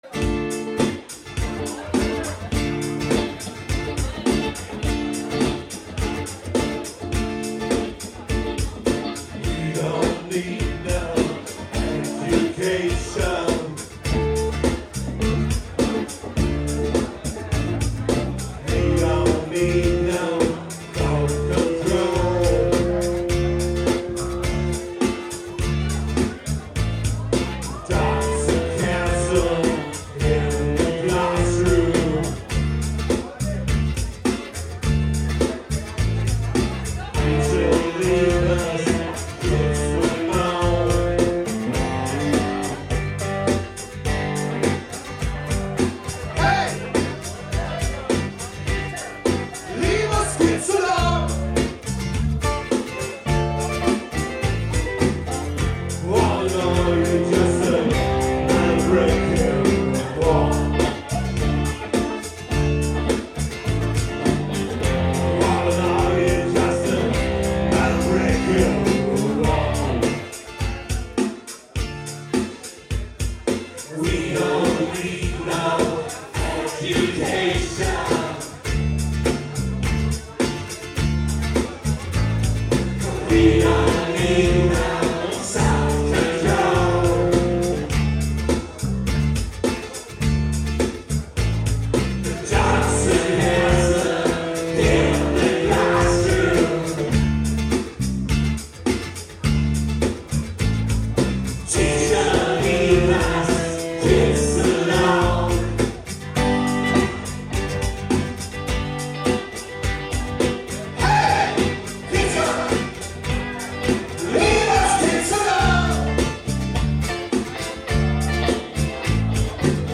Hier ein paar Soundbeispiele (alles live und im Trio)